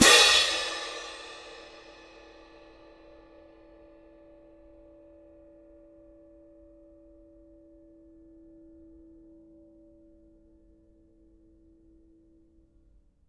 Percussion
cymbal-crash1_ff_rr2.wav